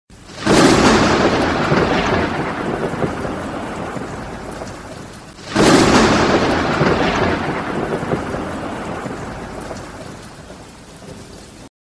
Звуки грозы, грома
Громовой удар молнии в небе